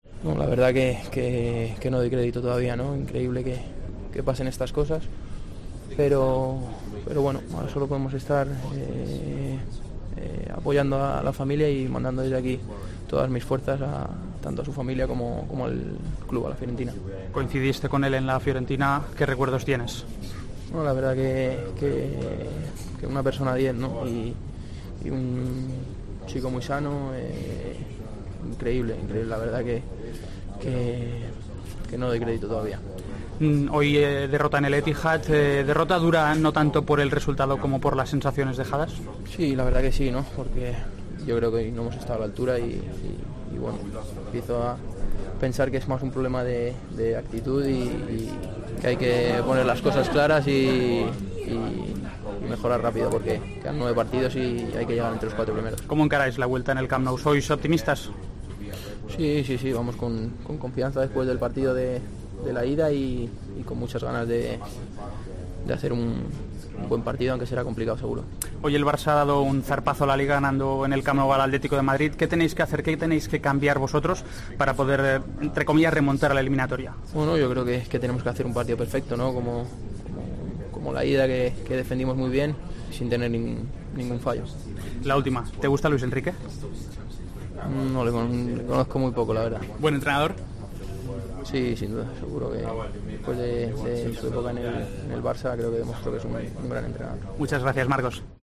Marcos Alonso, jugador del Chelsea, habló para Tiempo de Juego tras la derrota ante el City: "Astori era una persona diez, un chico muy sano, no doy crédito todavía.